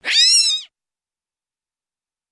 Звуки летучих мышей
На этой странице представлены записи эхолокационных сигналов, социальных криков и ночной активности разных видов.